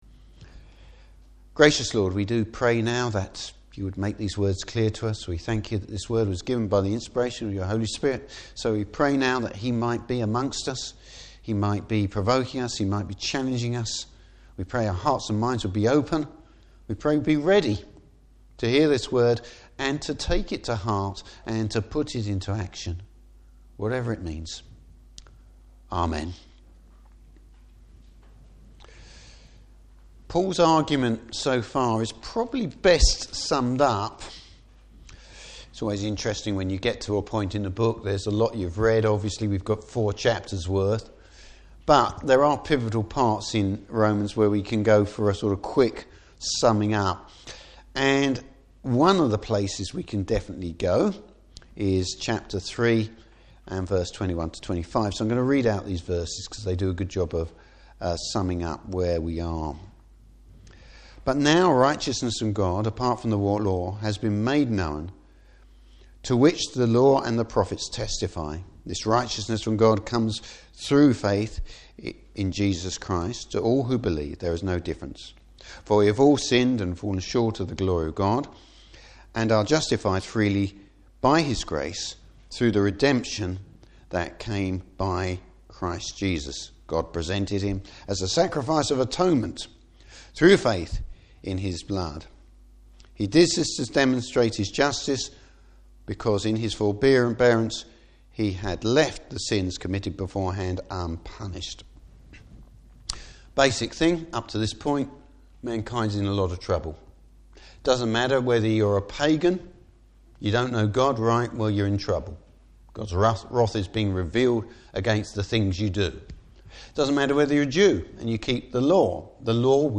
Service Type: Morning Service How we have peace and joy with God.